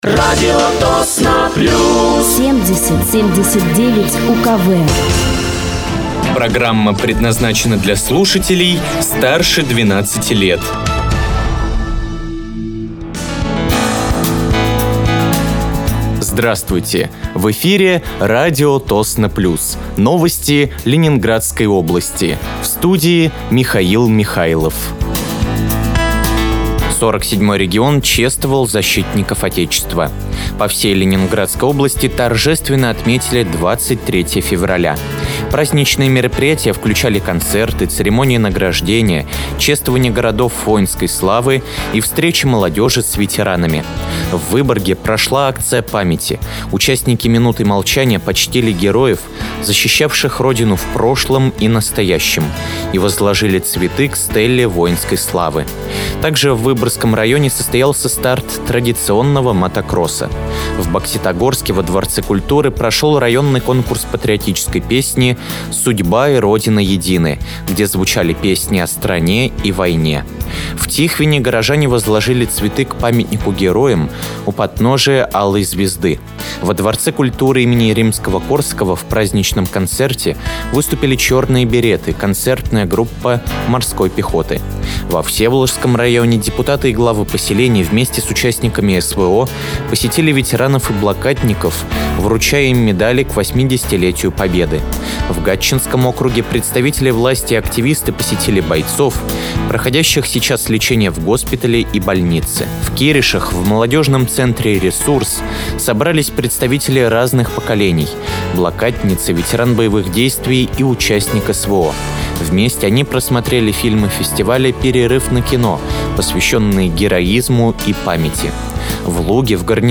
Вы слушаете новости Ленинградской области от 24.02.2025 на радиоканале «Радио Тосно плюс».